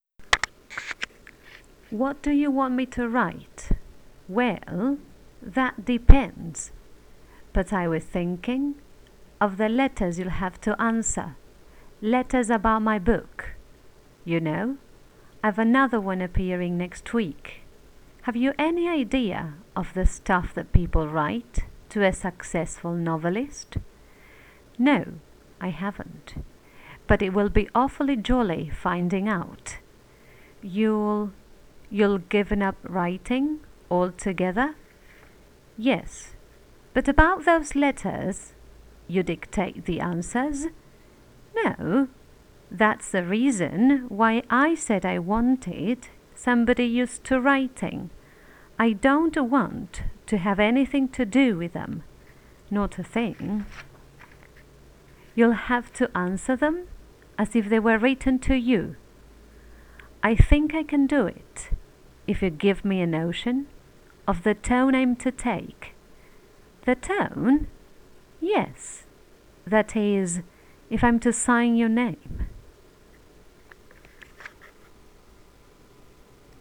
Full Circle Dictation - intonation.wav